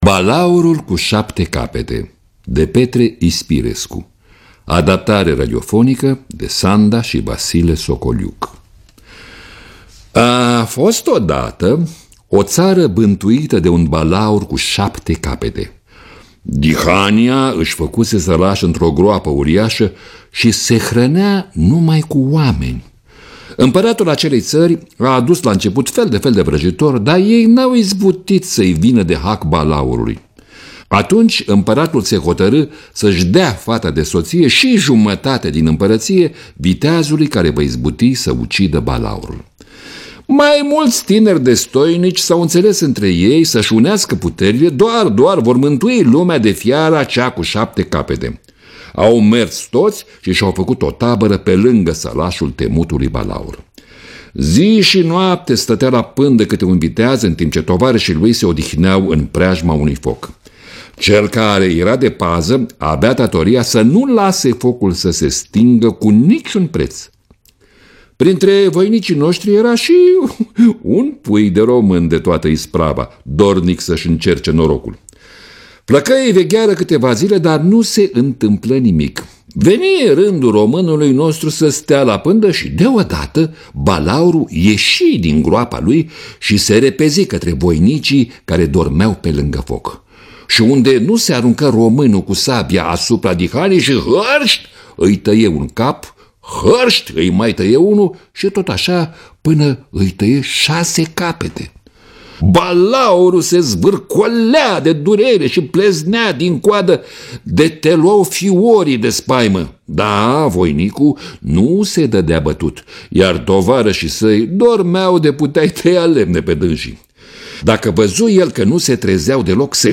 Adaptarea radiofonică de Sanda și Vasile Socoliuc. Lectura: Mircea Albulescu.